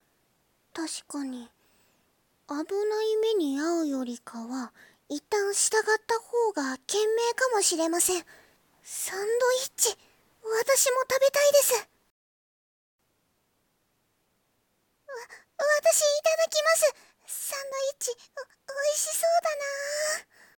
💐声劇 ①